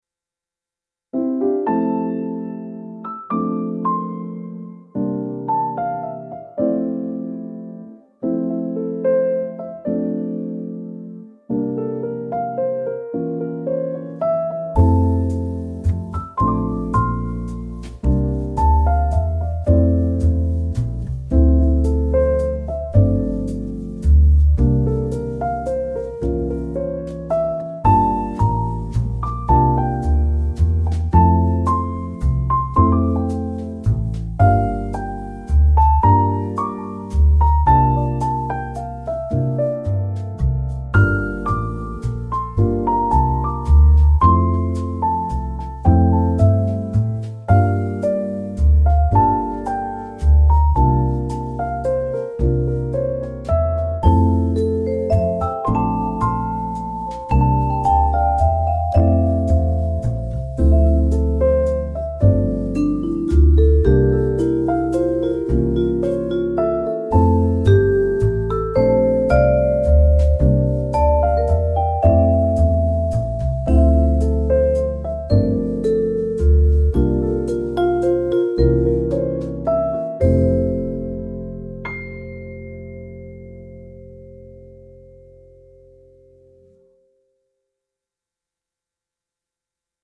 smooth lounge piano with subtle vibraphone and soft upright bass